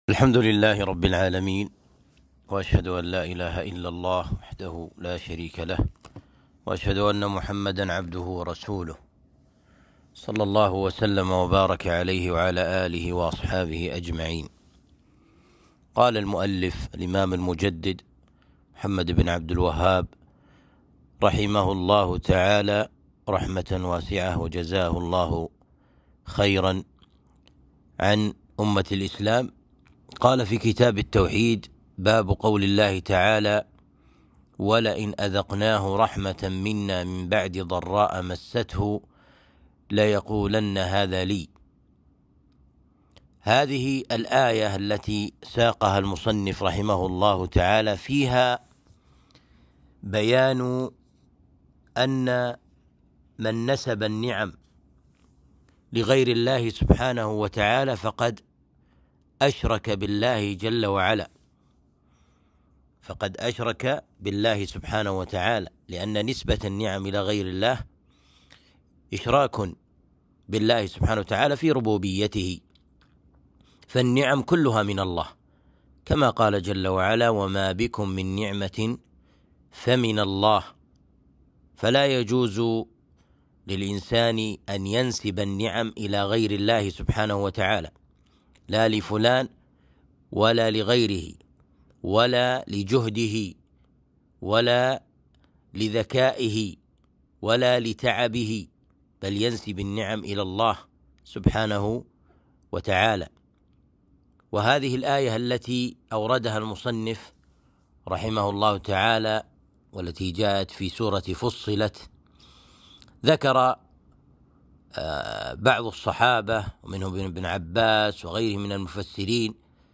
الدروس